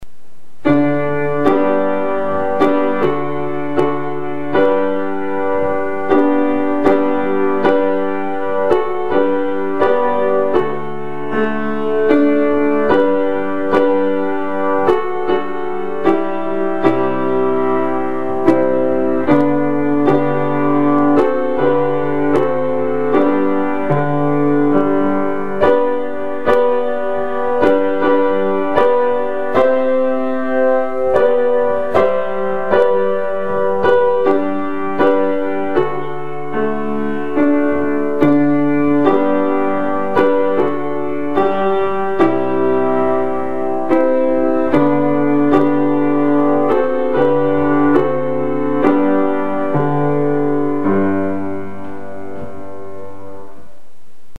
旧校歌（シートマイヤーのピアノで演奏）
piano002.mp3